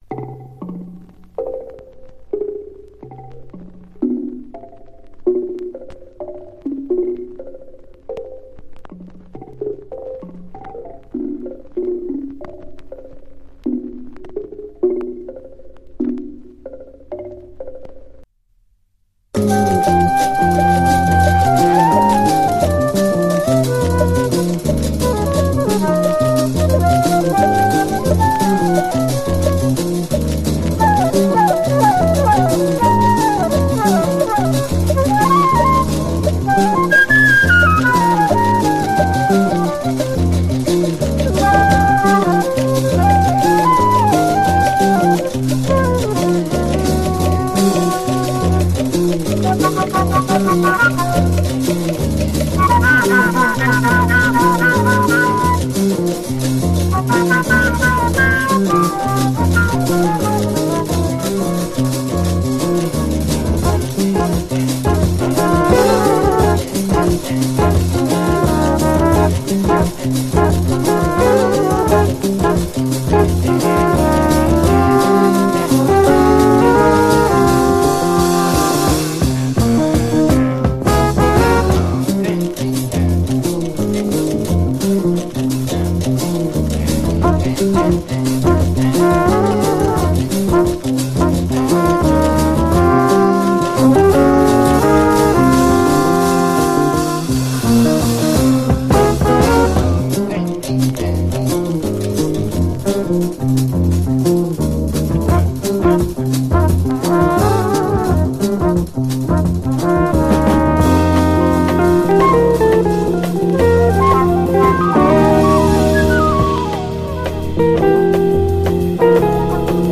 イイ曲だらけのオランダ産ファンキー・ミドルスクール〜ヒップ・ハウス！